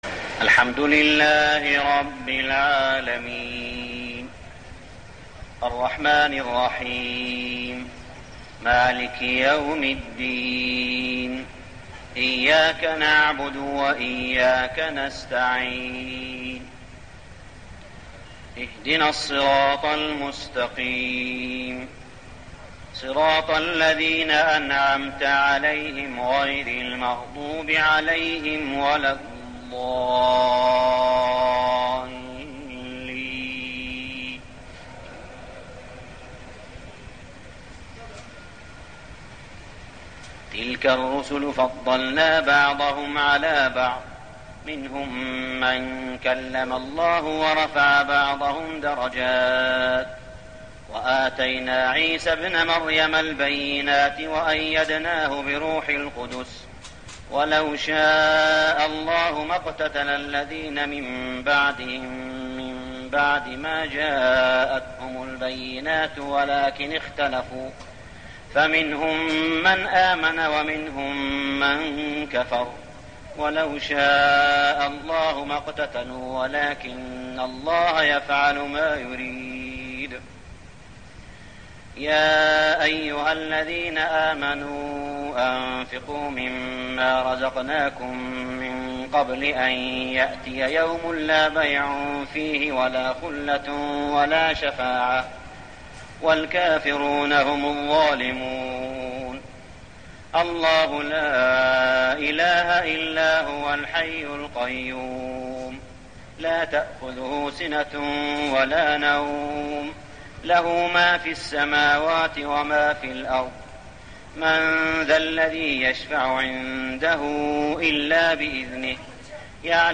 صلاة التراويح ليلة 4-9-1410هـ سورتي البقرة 253-286 و آل عمران 1-14 | Tarawih prayer Surah Al-Baqarah and Al-Imran > تراويح الحرم المكي عام 1410 🕋 > التراويح - تلاوات الحرمين